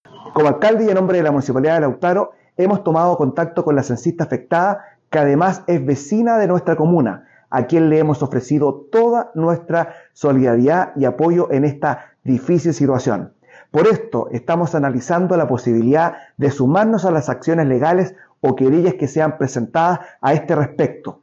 Similar accionar anticipó la Municipalidad de Lautaro, según precisó el alcalde, Ricardo Jaramillo